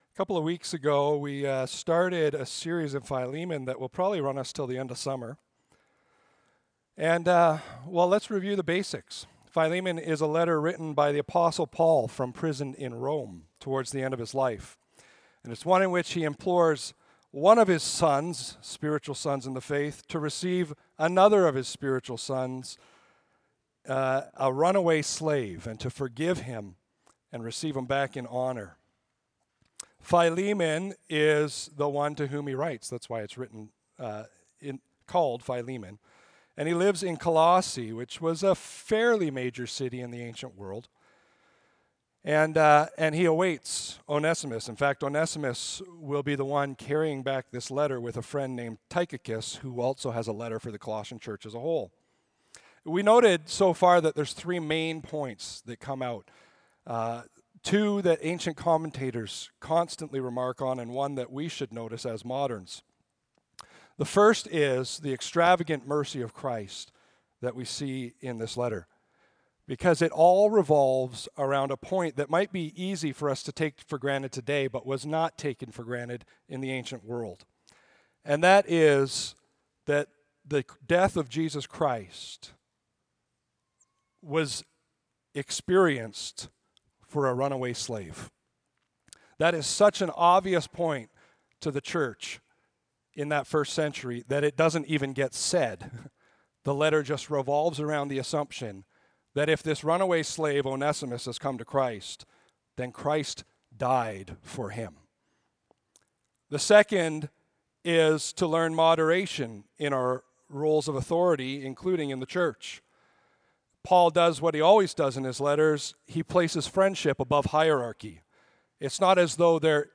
Audio Sermon Library